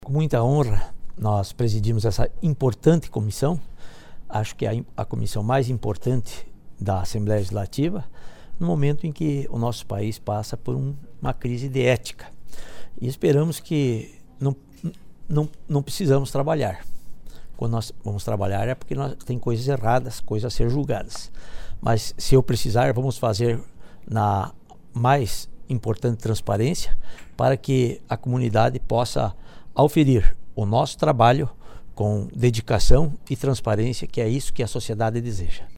Ouça abaixo o que disseram os presidentes das demais comissões instaladas nesta quarta-feira:
Antonio Aguiar (PMDB) - Comissão de Ética e Decoro Parlamentar